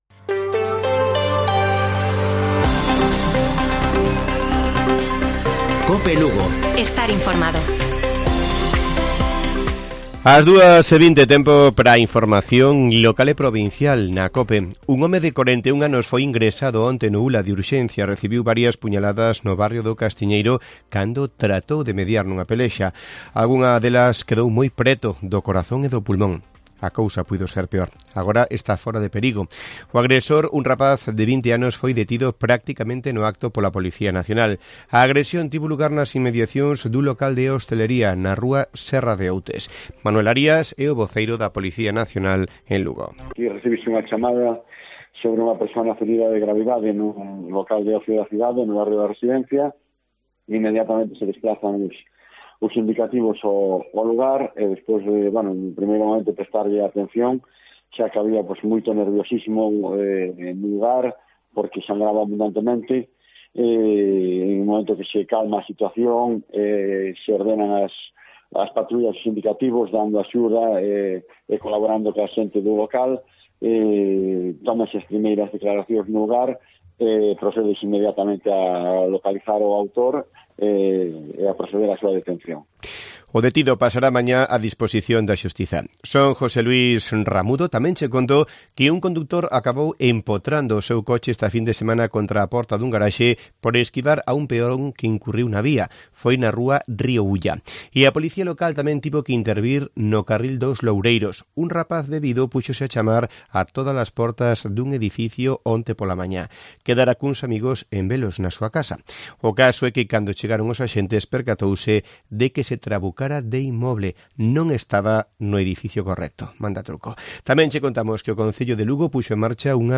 Informativo Mediodía de Cope Lugo. 17 de julio. 14:20 horas